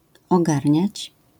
wymowa:
IPA[ɔˈɡarʲɲät͡ɕ], AS[ogarʹńäć], zjawiska fonetyczne: zmięk.podw. art.